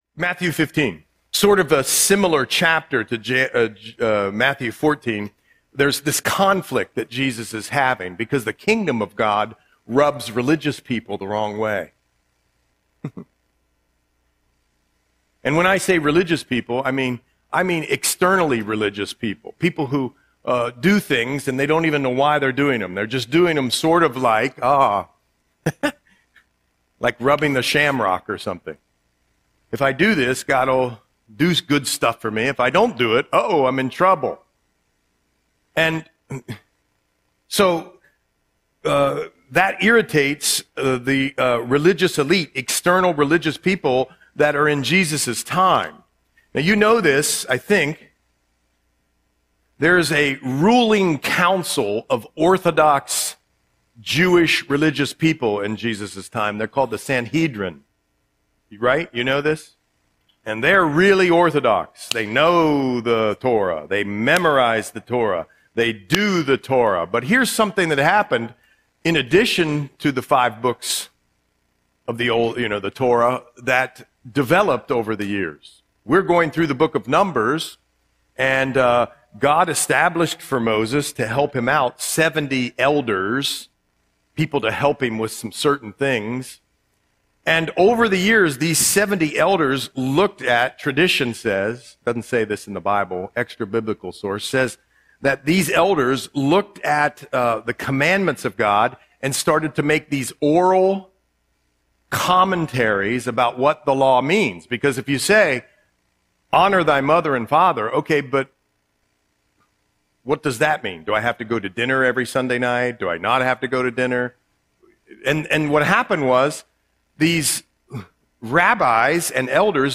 Audio Sermon - March 15, 2026